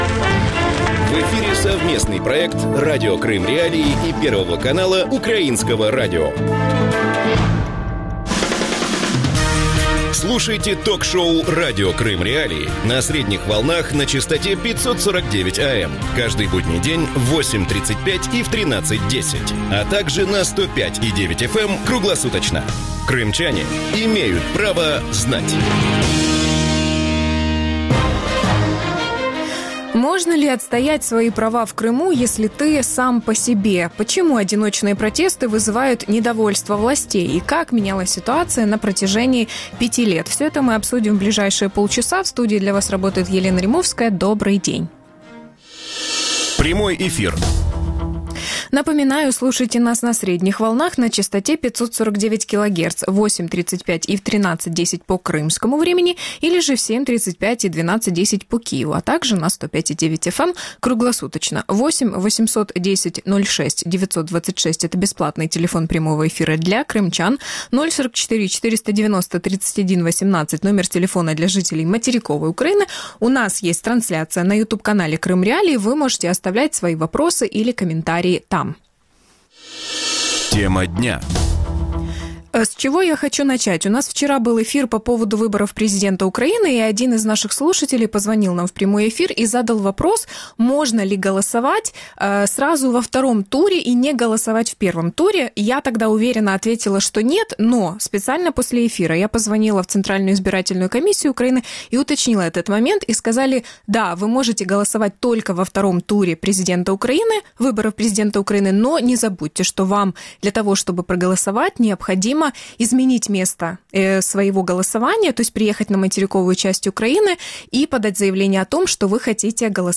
Почему протесты крымчан вызывают недовольство российских властей? Гости эфира